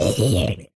zombiehurt.ogg